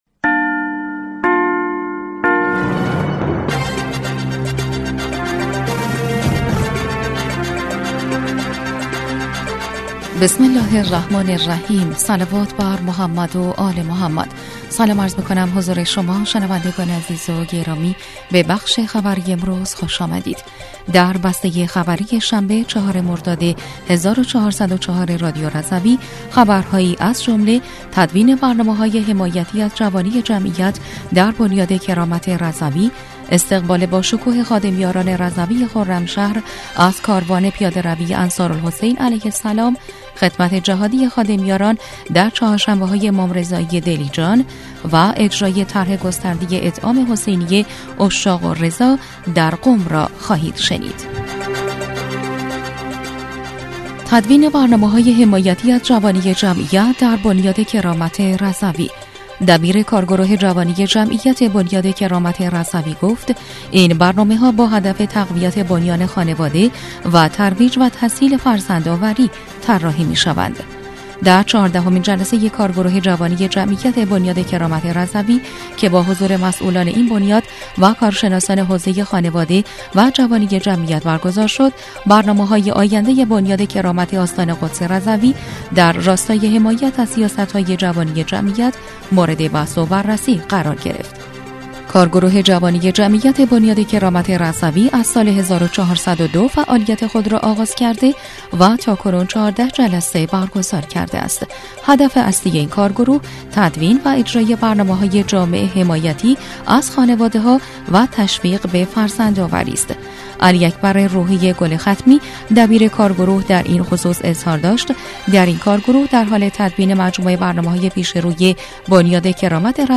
بسته خبری ۴ مردادماه ۱۴۰۴ رادیو رضوی/